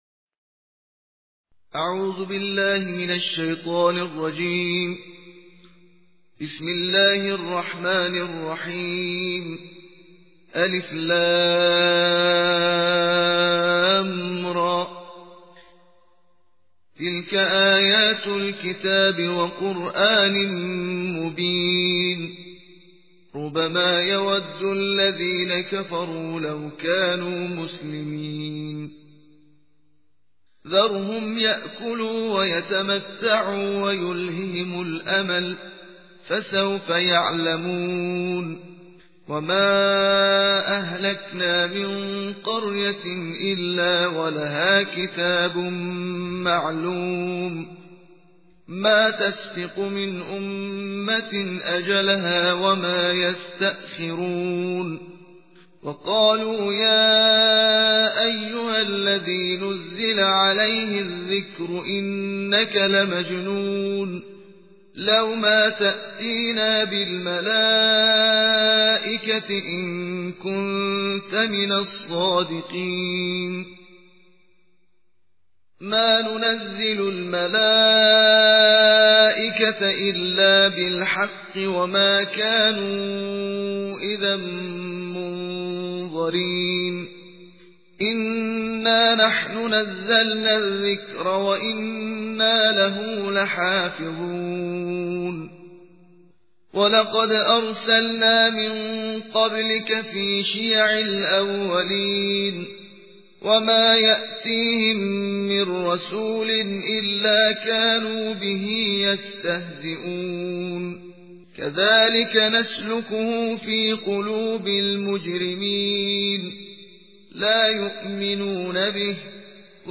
صوت/ ترتیل جزء چهاردهم قرآن